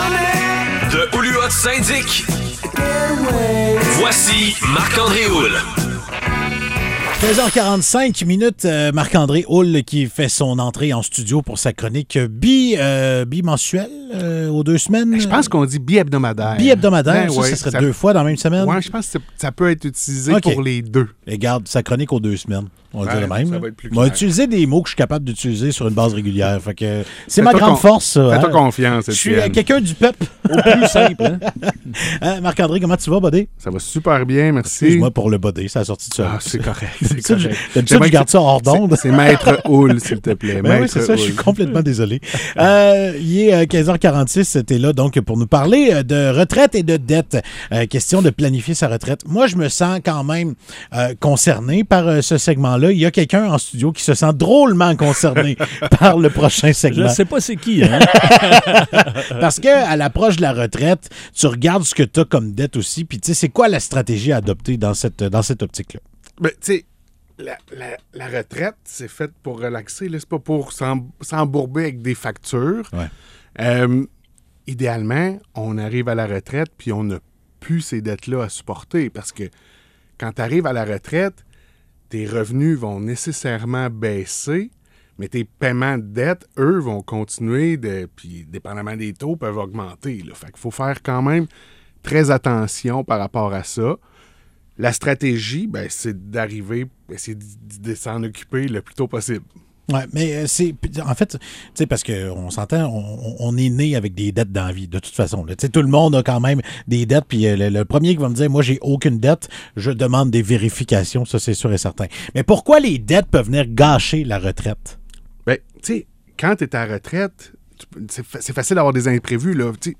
Chronique Radio